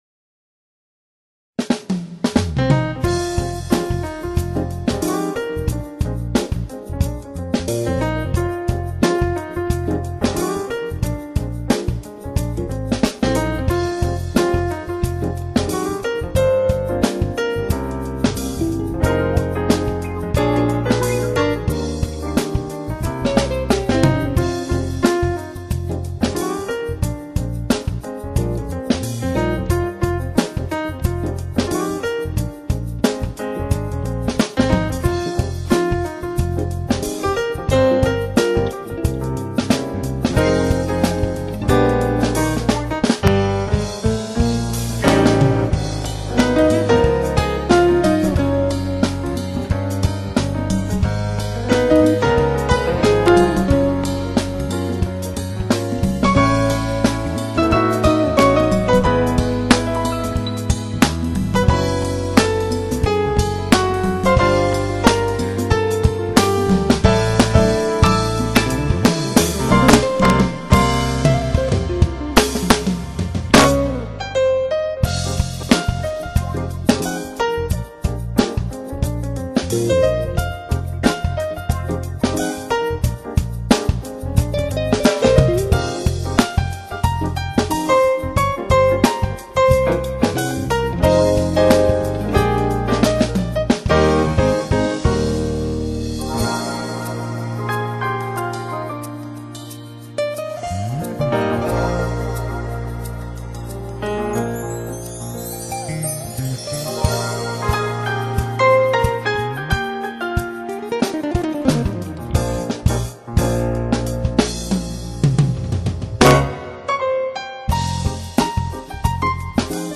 音乐成熟干练，声部清晰明快，配器精致独到，乐手的演奏功底深厚。
CD中12首曲子，总体来说主要体现钢琴的演奏，但风格多样化，从中你会找到你所喜欢的音乐。
这是一张清丽美妙的jazz fusion唱片，浓郁的lounge气息直教人陶醉。